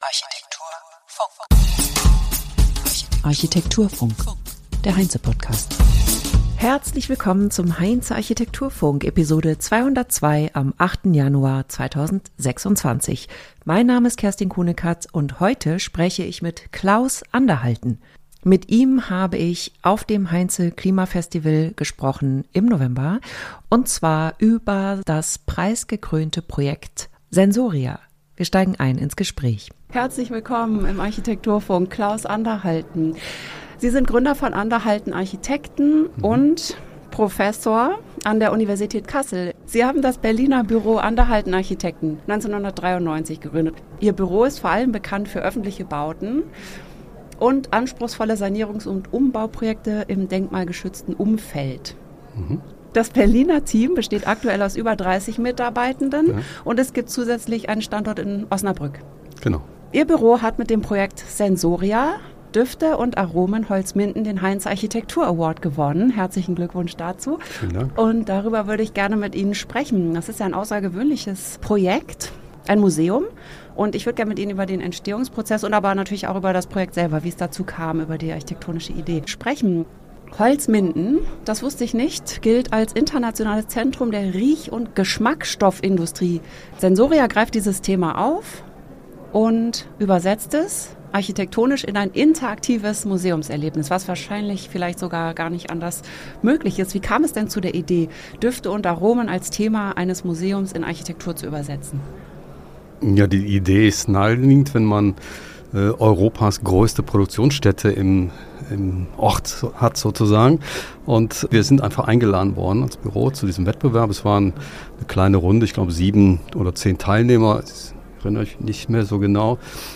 Ein Gespräch über Wahrnehmung, Haltung und die Frage, wie Architektur dem Flüchtigen einen Rahmen geben kann.